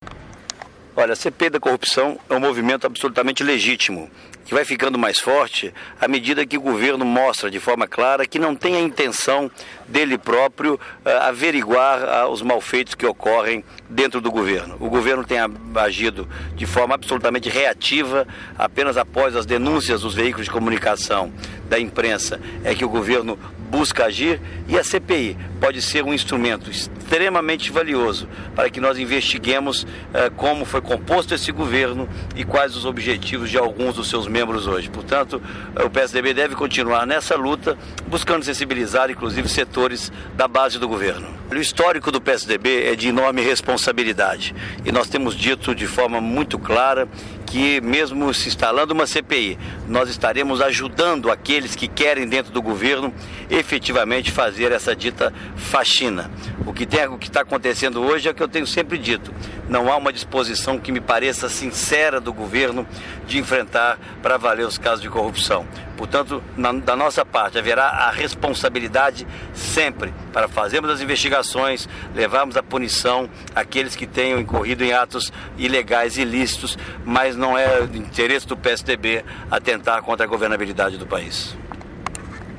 Declaração do senador Aécio Neves sobre a CPI da Corrupção